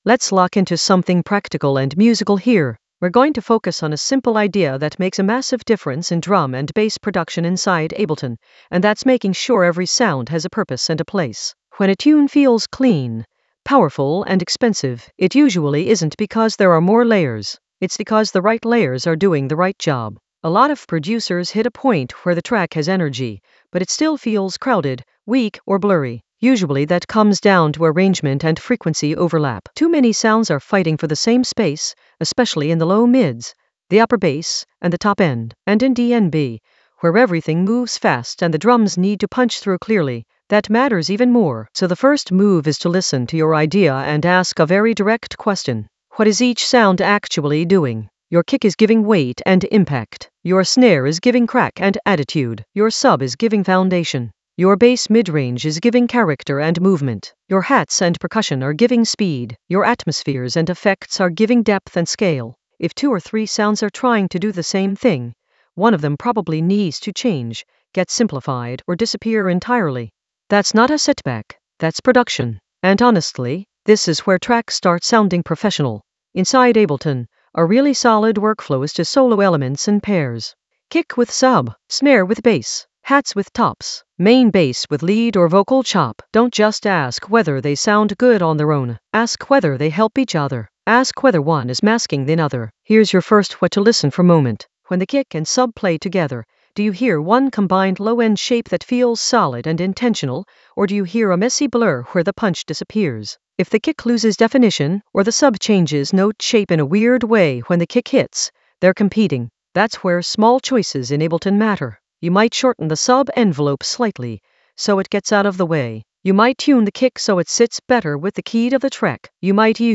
An AI-generated beginner Ableton lesson focused on Alix Perez sub basslines that shake in the Basslines area of drum and bass production.
Narrated lesson audio
The voice track includes the tutorial plus extra teacher commentary.